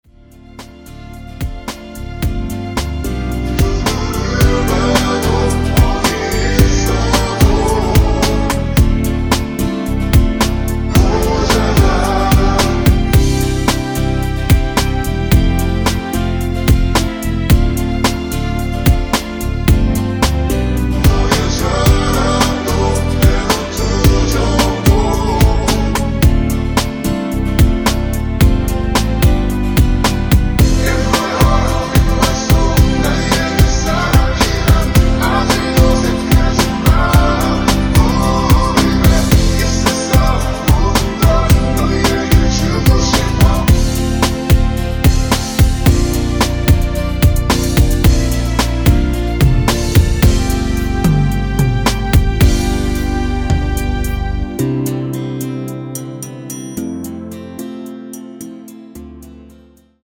원키에서(-3)내린 코러스 포함된 MR입니다.(미리듣기 확인)
Bb
앞부분30초, 뒷부분30초씩 편집해서 올려 드리고 있습니다.
중간에 음이 끈어지고 다시 나오는 이유는